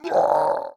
MONSTER_Hurt_mono.wav